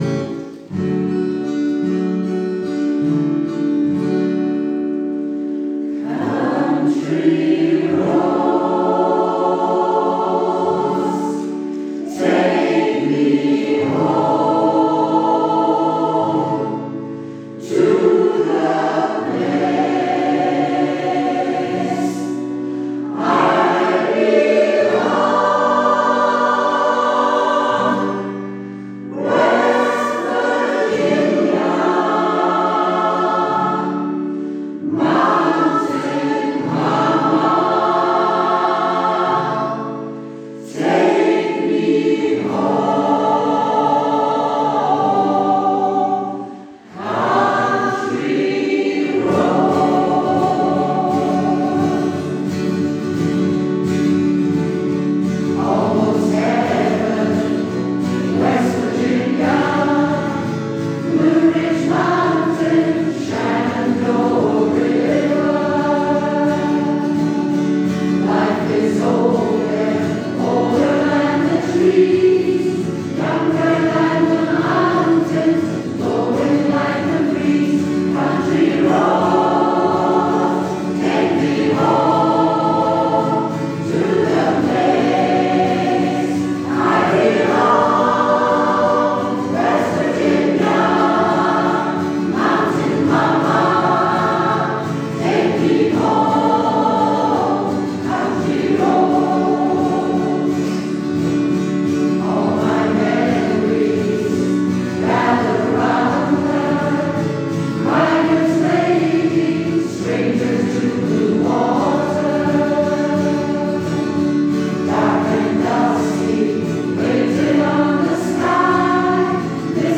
community choir
And here are some audio recordings from a recent concert: